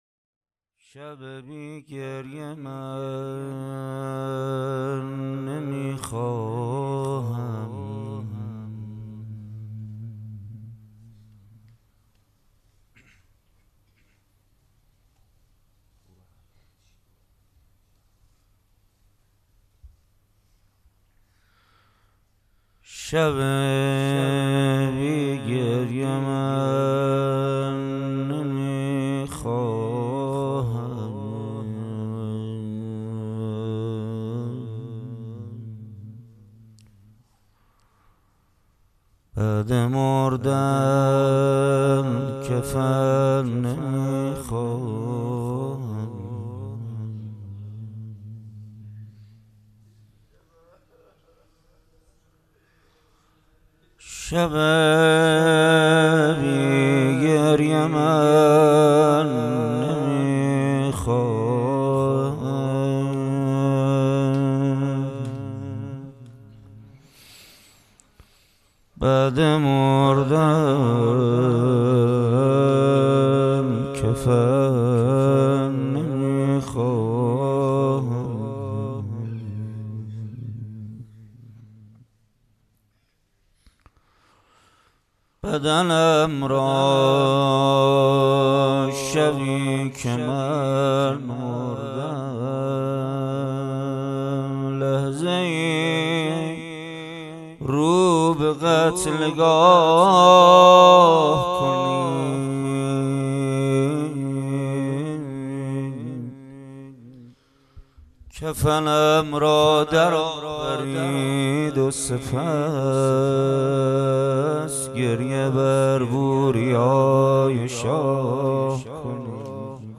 دانلود مداحی جدید کربلایی محمد حسین پویانفر مراسم هفتگی سه شنبه 17 تیر 99 هیات ریحانة النبی(س)